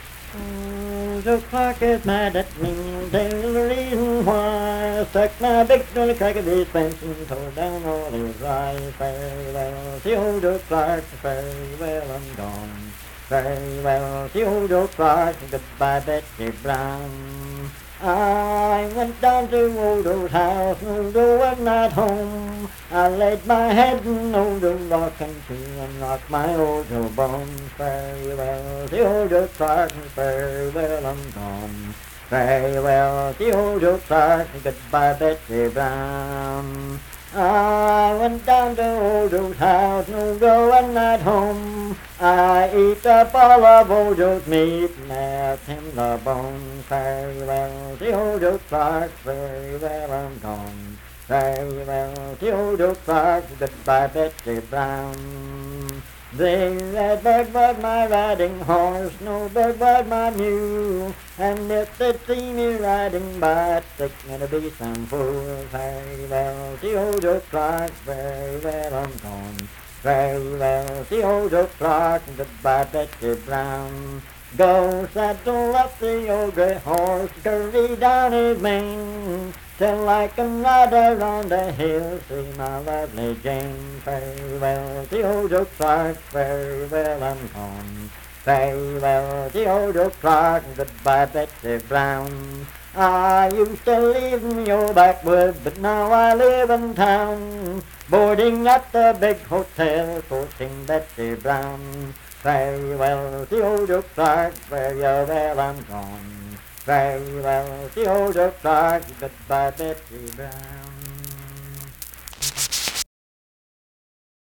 Unaccompanied vocal music
Verse-refrain 6(8w/R).
Performed in Dundon, Clay County, WV.
Dance, Game, and Party Songs
Voice (sung)